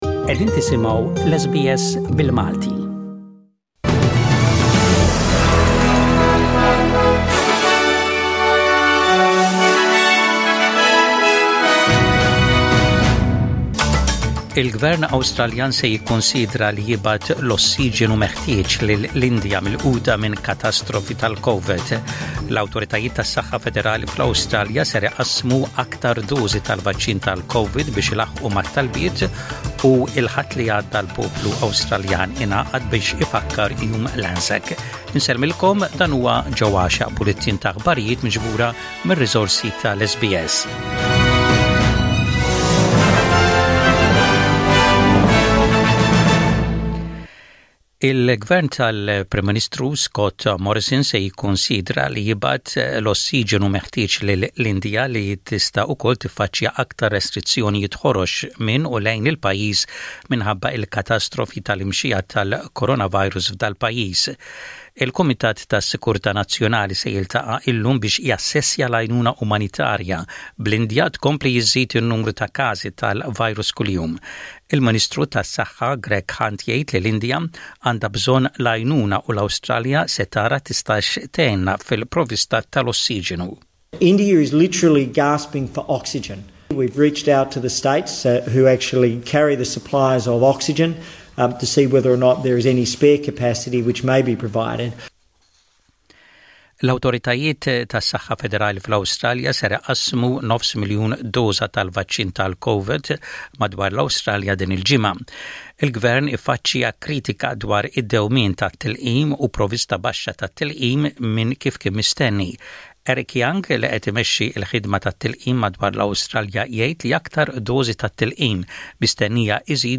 SBS Radio | Maltese News: 27/04/21